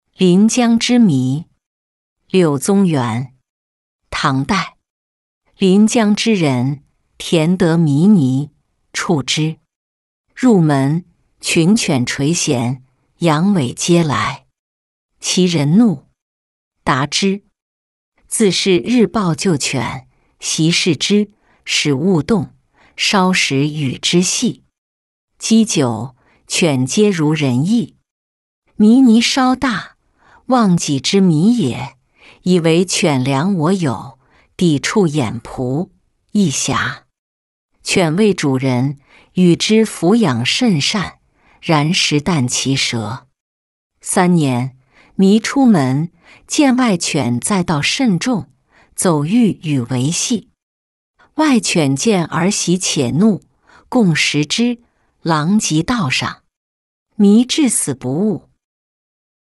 临江之麋-音频朗读